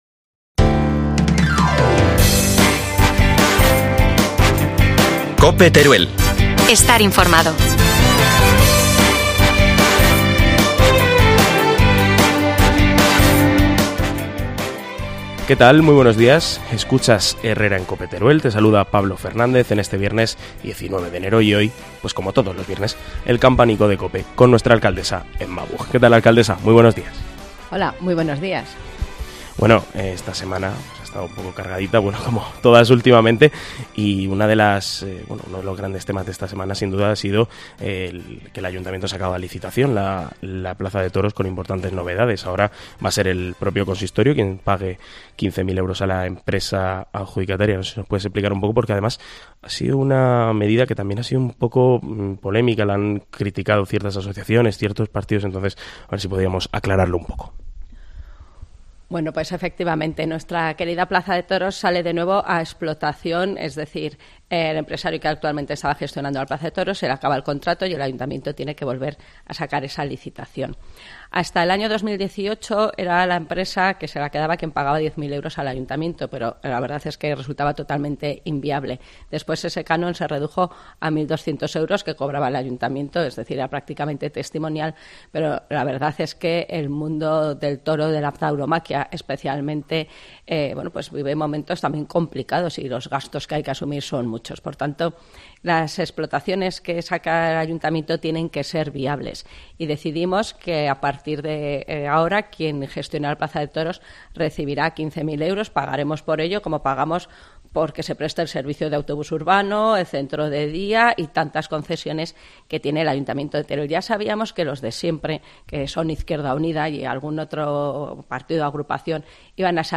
AUDIO: Como todos los viernes, el Campanico de COPE con la alcaldesa de Teruel, Emma Buj